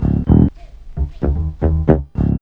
4308L B-LOOP.wav